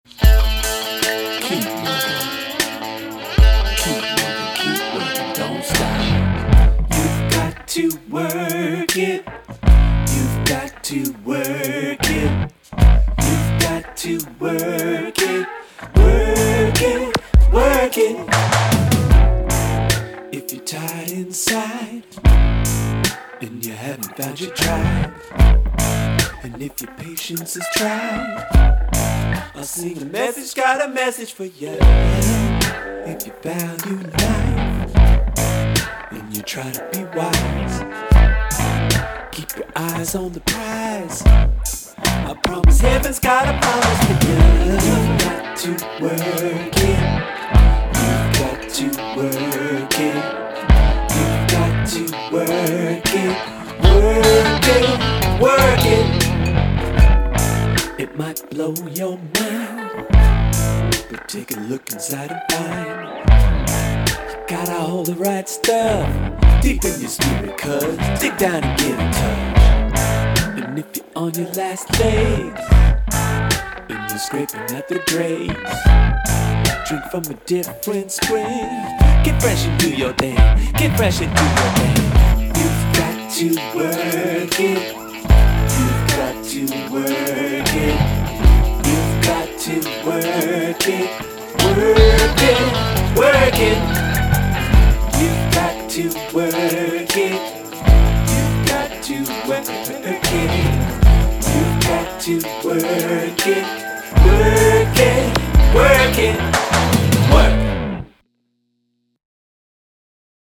Here’s something (rough) for the ears: